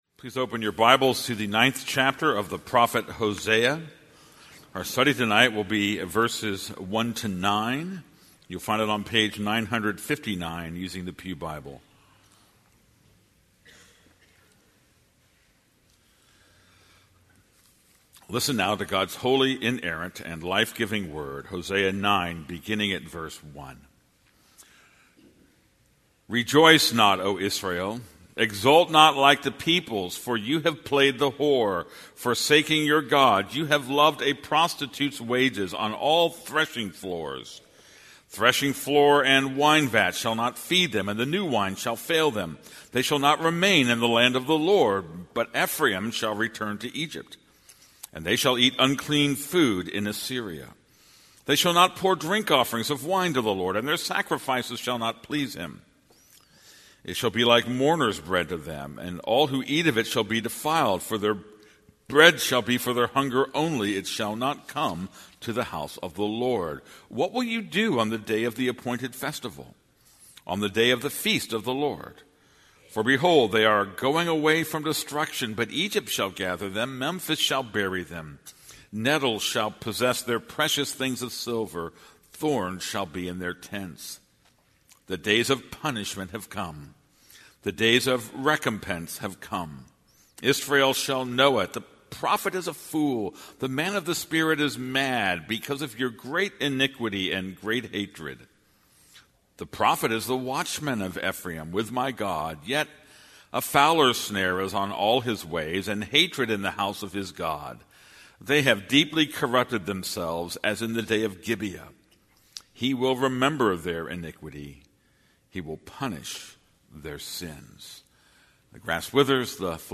This is a sermon on Hosea 9:1-9.